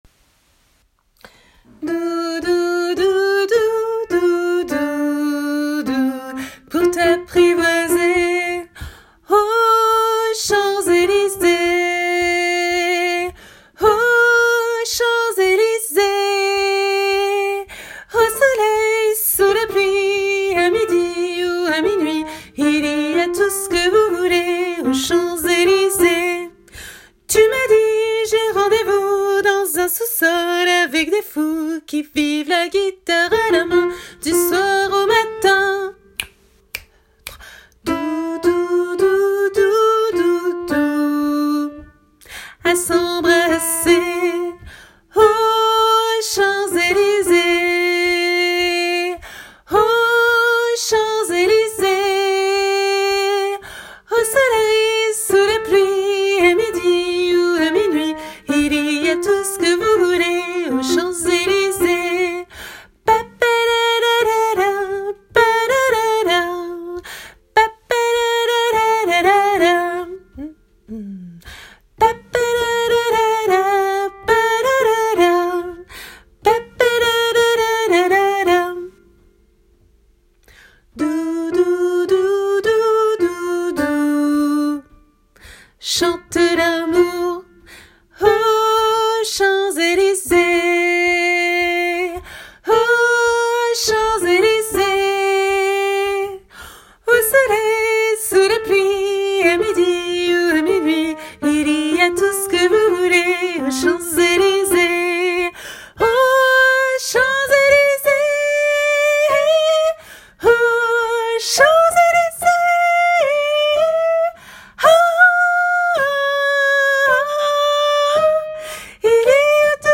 MP3 versions chantées (les audios sont téléchargeables)
Soprano